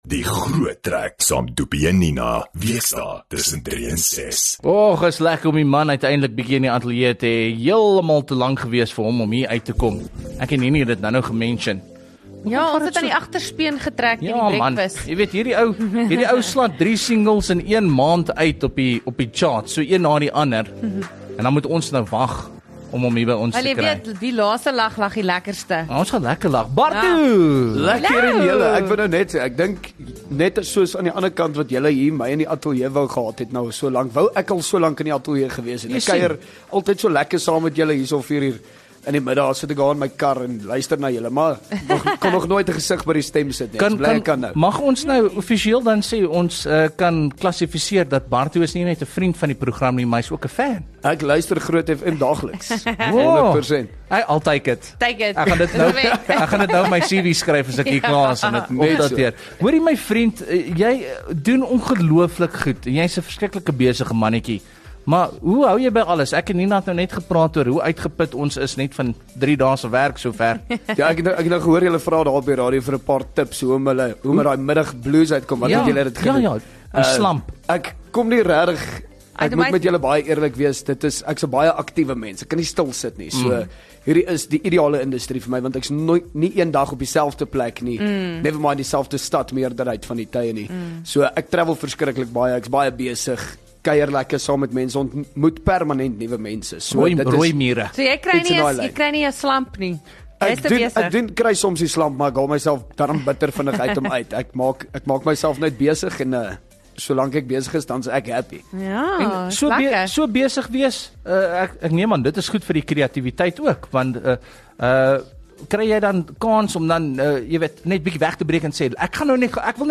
kom vermaak die luisteraars met van sy gewildste liedjies akoesties